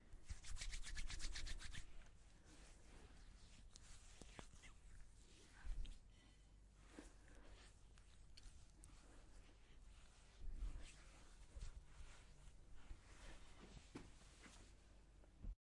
涂抹面霜
描述：把面霜涂抹在脸上，非常奶油。 使用ZoomH6录制，作为立体声的48kHz 24bit WAV文件。
标签： 晚上 变化 节奏 命中 声音 刷涂 湿润 湿 潮湿 各种的 潮湿 手指 奶油 OWI 奶油 早晨 触摸 皮肤 温和 摩擦
声道立体声